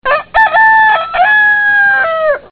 دانلود صدای خروس مریض و بد صدا مخصوص زنگ بیدار باش از ساعد نیوز با لینک مستقیم و کیفیت بالا
جلوه های صوتی